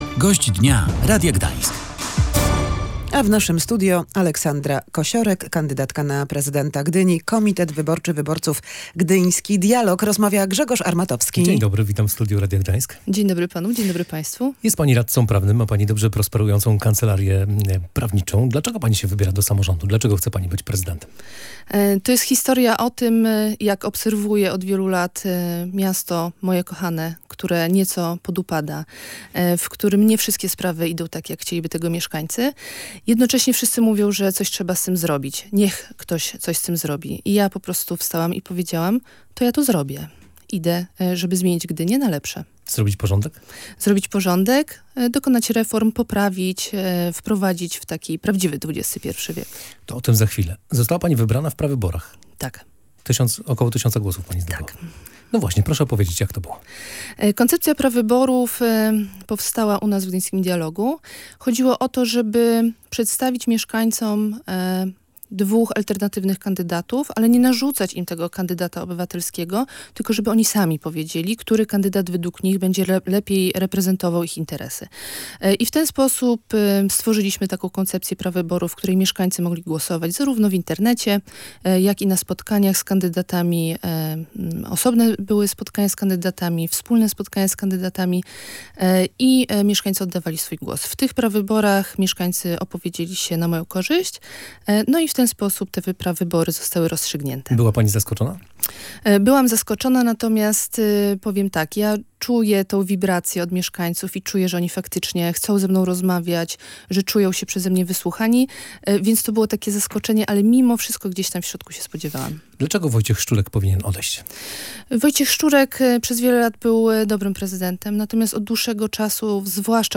Gość Dnia Radia Gdańsk